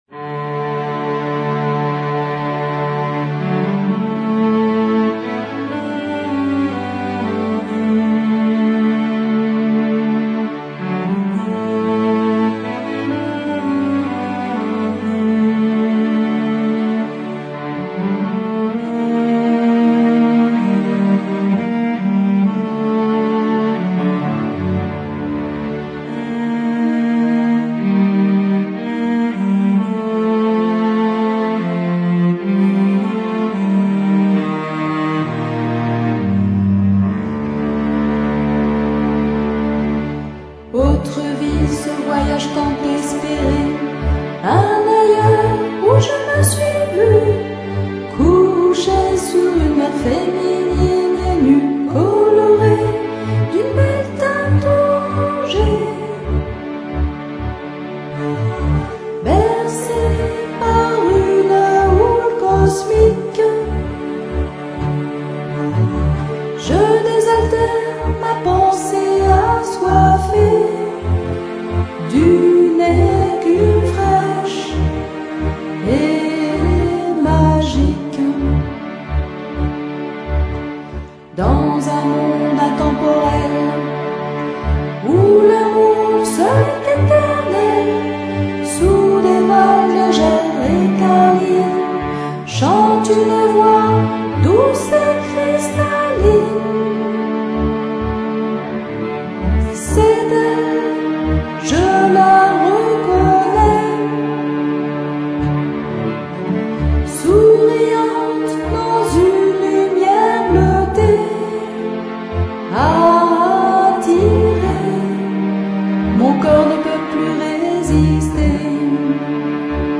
Sculpture sonore